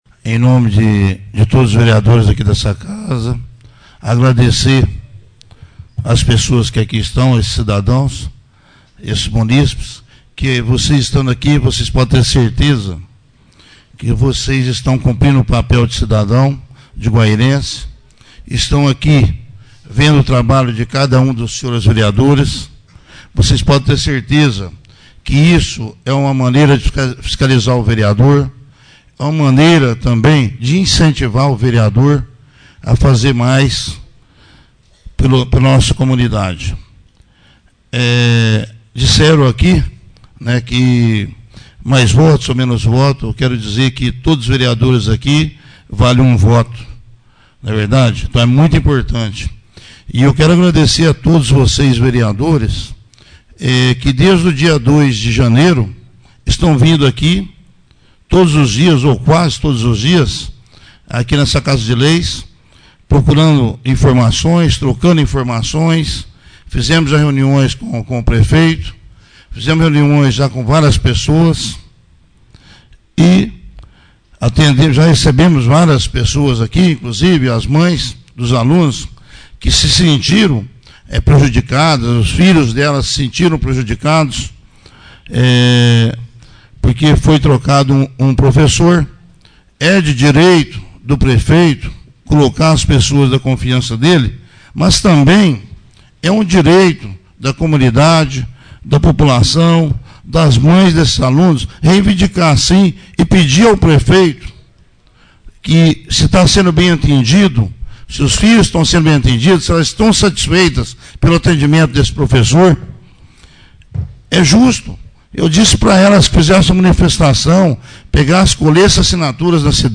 O Presidente da Câmara Municipal de Guaíra e vereador José Mendonça (PDT), finalizou a primeira Sessão Ordinária do ano de 2013. Destaque para seus agradecimentos e suas principais indicações já apresentadas